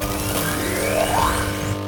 charge1.ogg